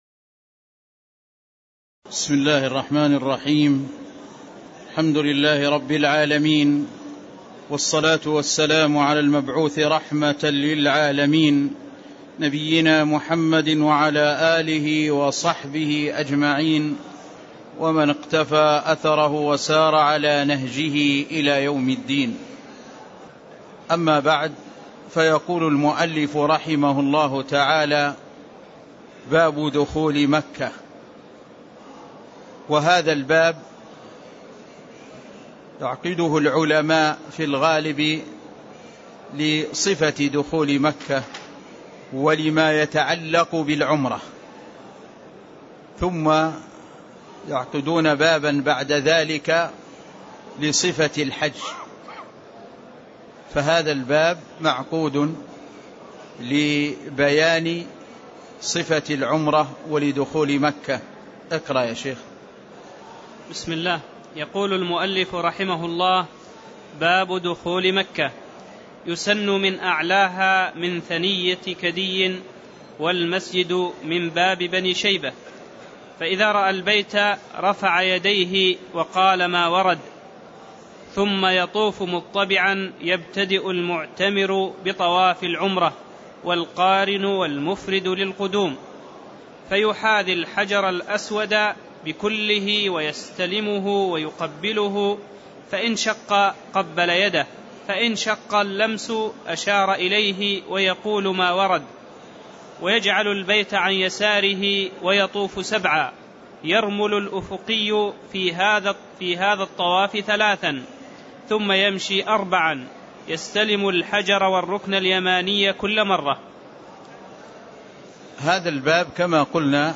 تاريخ النشر ٤ ذو الحجة ١٤٣٥ هـ المكان: المسجد النبوي الشيخ